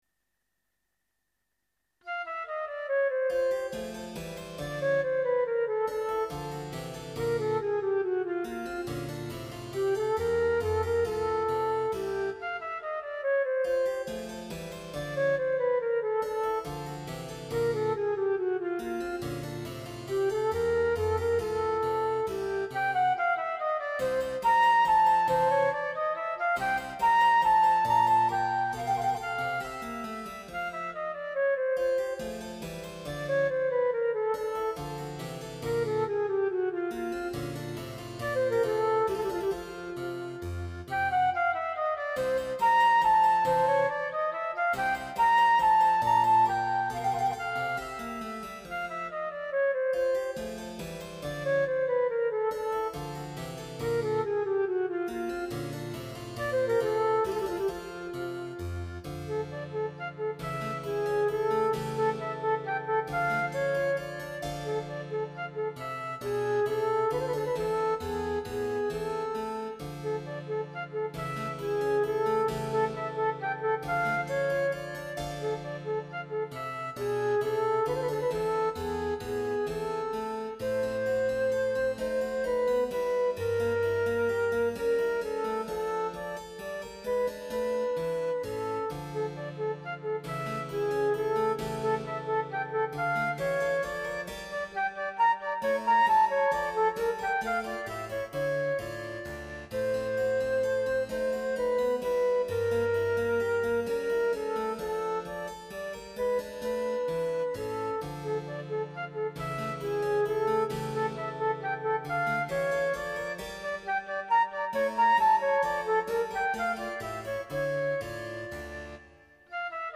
(Sonata for Flute and Harpsichord in F major, K.13)
第3楽章／メヌエット (III. Menuetto)
MIDIデータ作成